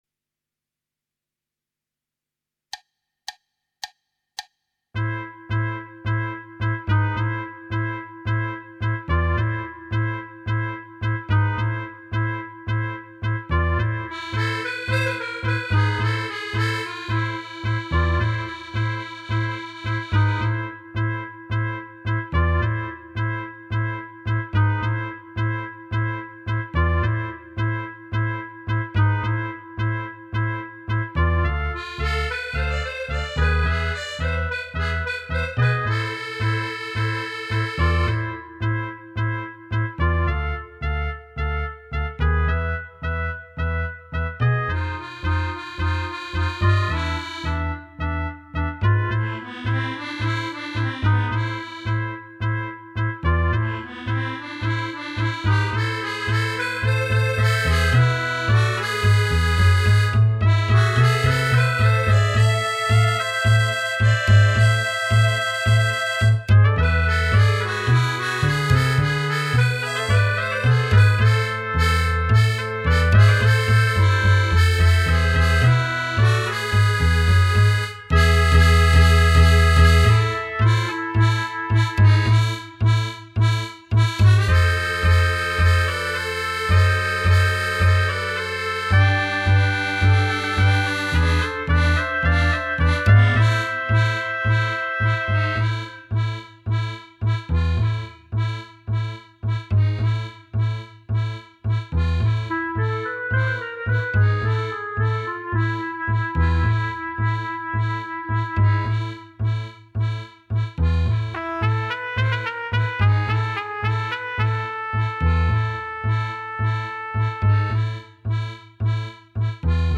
Right click to download Tango minus Instrument 3